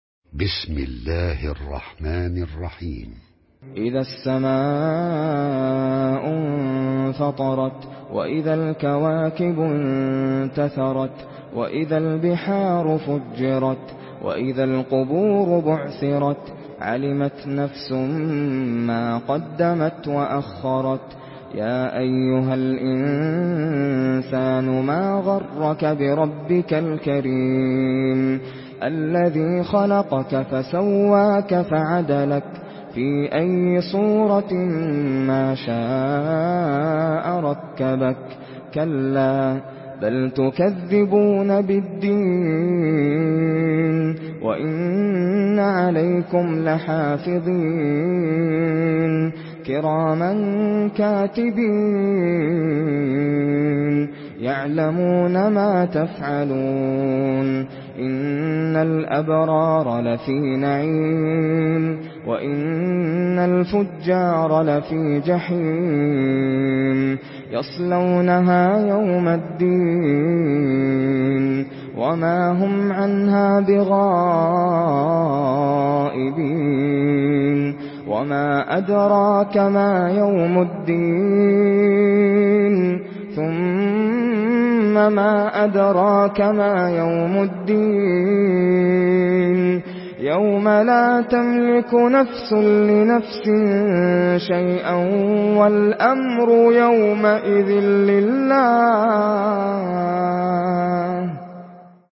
سورة الانفطار MP3 بصوت ناصر القطامي برواية حفص
مرتل